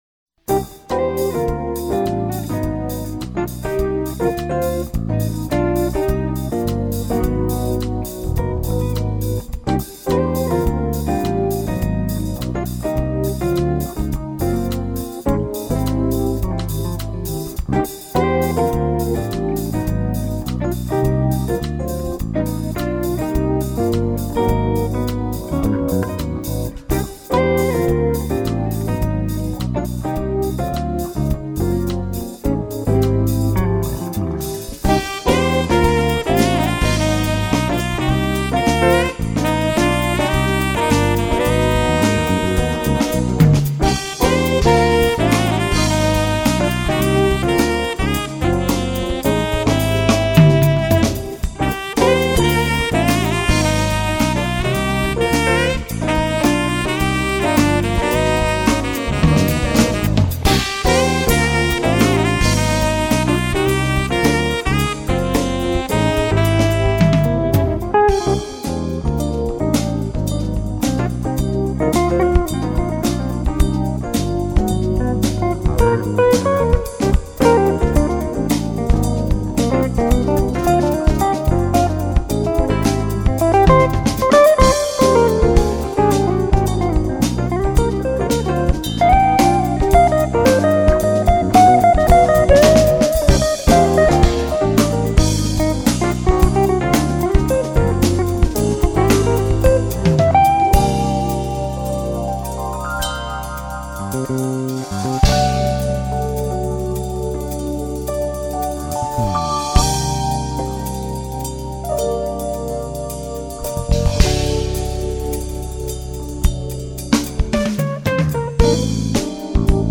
1414   05:24:00   Faixa:     Jazz